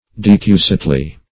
decussately - definition of decussately - synonyms, pronunciation, spelling from Free Dictionary Search Result for " decussately" : The Collaborative International Dictionary of English v.0.48: Decussately \De*cus"sate*ly\, adv.
decussately.mp3